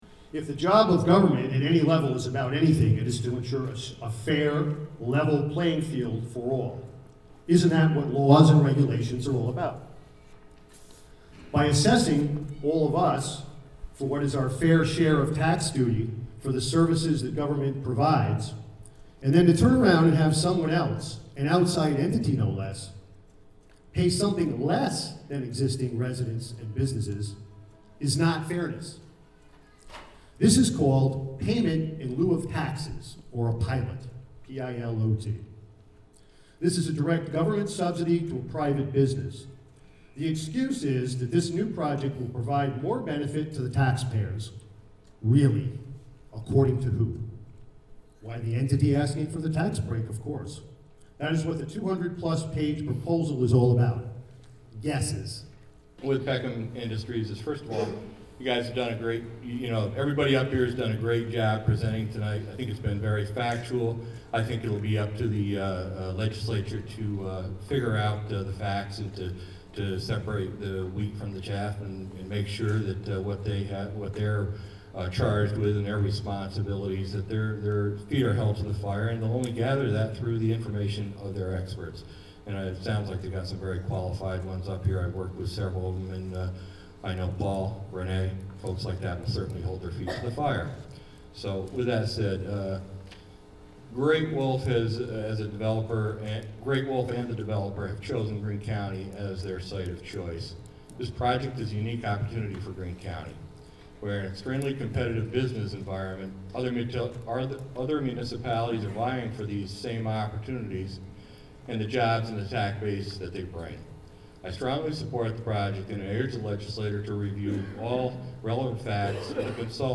Four public speakers at Greene IDA meeting about proposed New Baltimore indoor water park. (Audio)
Recorded Thu., Dec. 8 at Catskill High School.